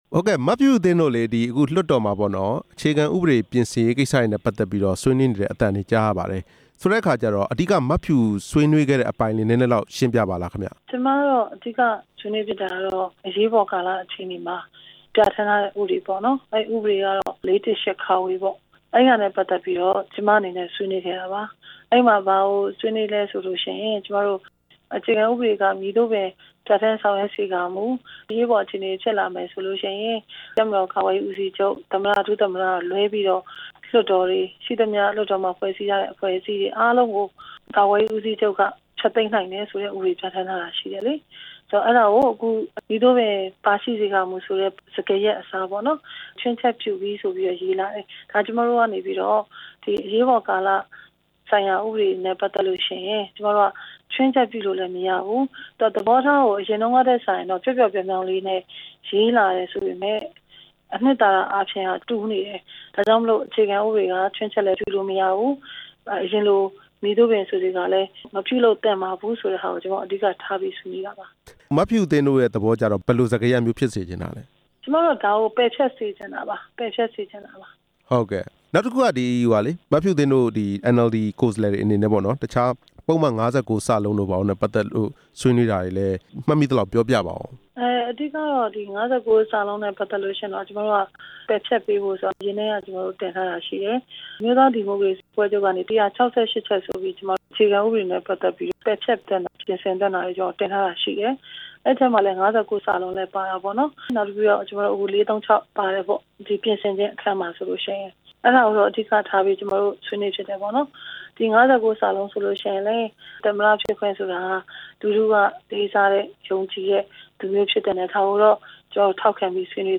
၂ဝဝ၈ ဖွဲ့စည်းပုံ အခြေခံဥပဒေပါ ပုဒ်မ ၅၉ (စ) ဟာ ဗိုလ်ချုပ်အောင်ဆန်း အမှူးပြုရေးဆွဲခဲ့တဲ့ ၁၉၄၇ အခြေခံဥပဒေမှာကတည်းက ပါရှိပြီးဖြစ်တယ်ဆိုတဲ့ ပြောဆိုချက်ဟာ လွဲမှားနေကြောင်း အန်အယ်လ်ဒီ လွှတ်တော်ကိုယ်စားလှယ် ဦးအောင်ကြည်ညွှန့်က ပြည်ထောင်စုလွှတ်တော်မှာ မနေ့က ဆွေးနွေးခဲ့ပါတယ်။